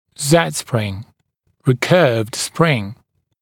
[zed-sprɪŋ] [ˌriː’kɜːvd sprɪŋ][зэд-сприн] [ˌри:’кё:вд сприн]Z-образная или двухконсольная пружина